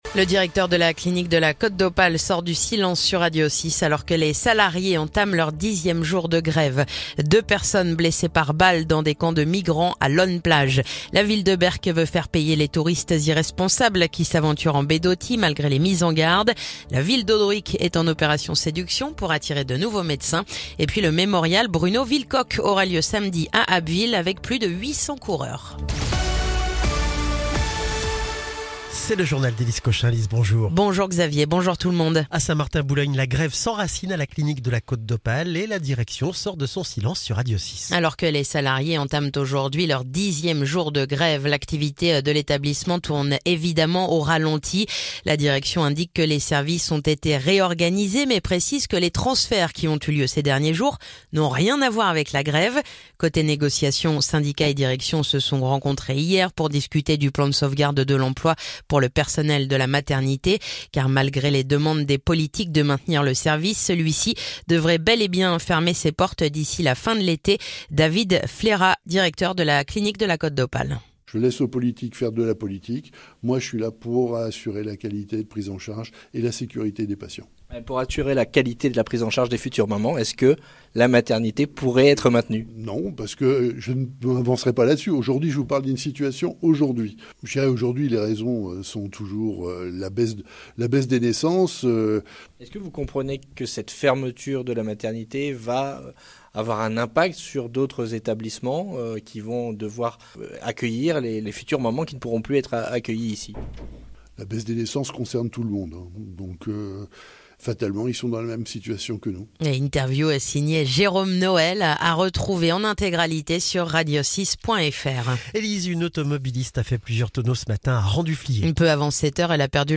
Le journal du mercredi 28 mai